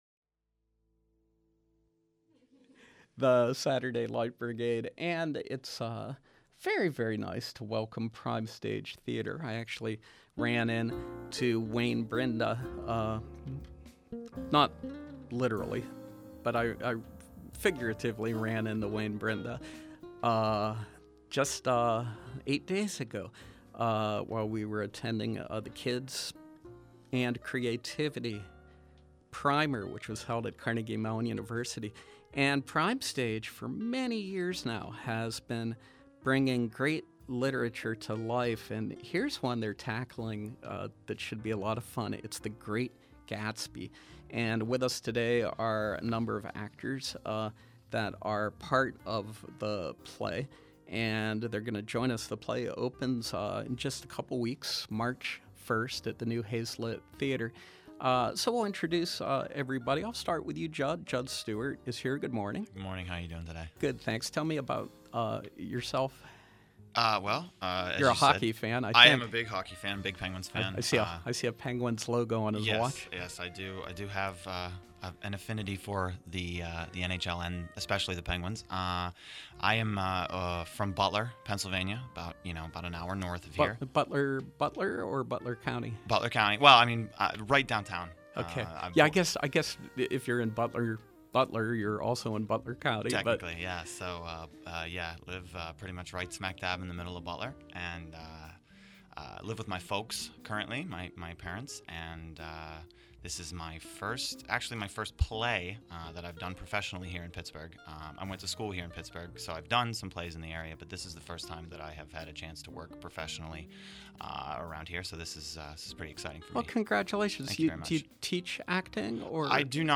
Live Preview: Prime Stage’s “The Great Gatsby”